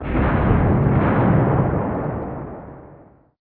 boom03.mp3